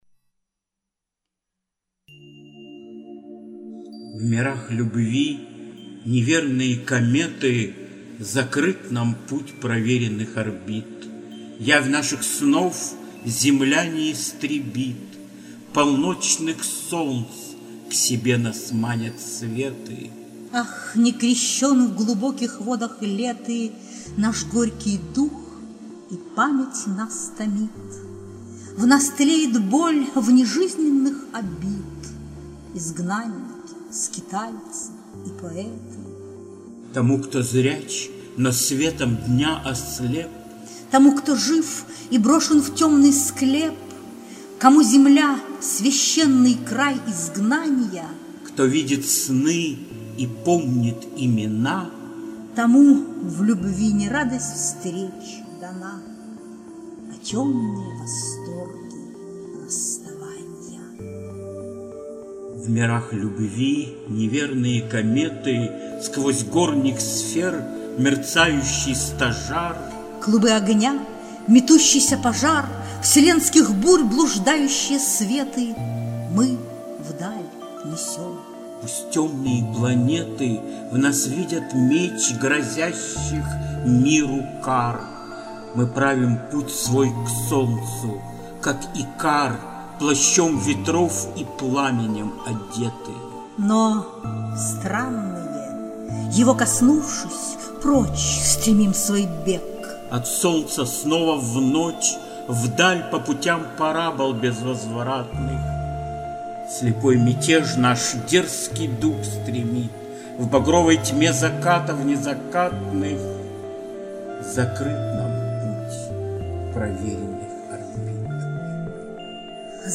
Волошин Максимилиан Александрович - Стихи и творчество (читает А.Демидова)